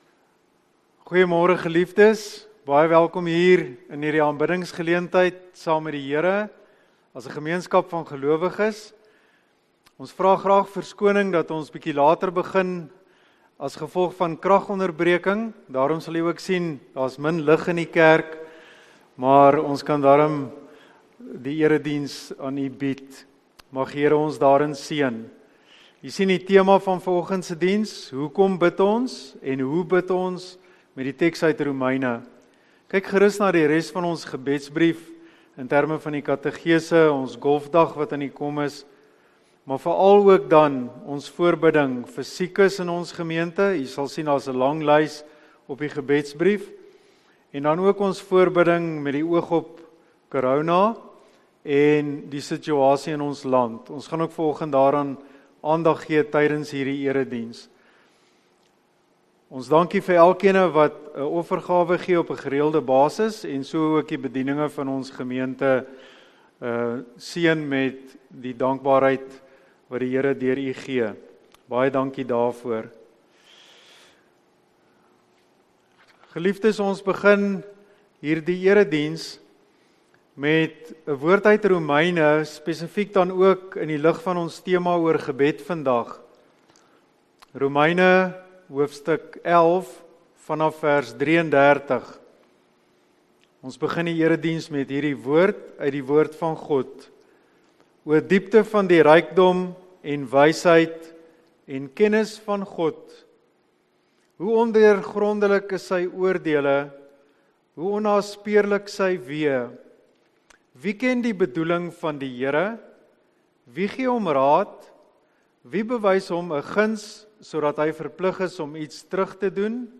Erediens